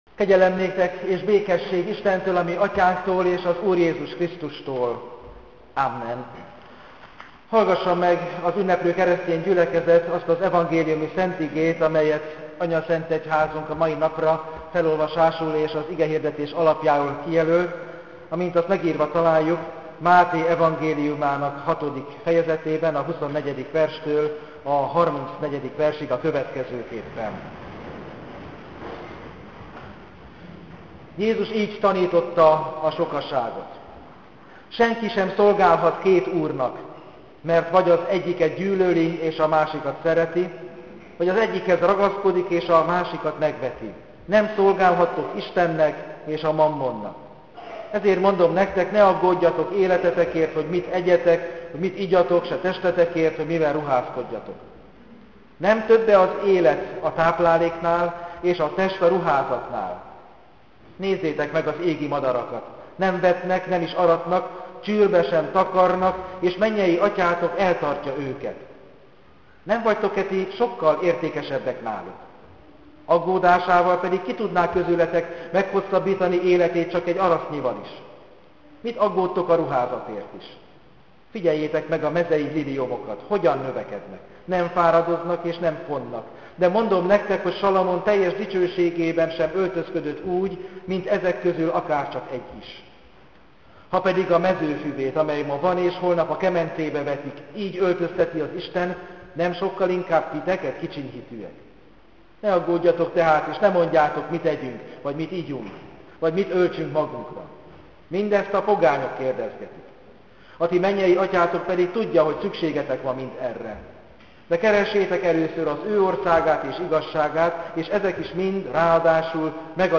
Az igehirdetés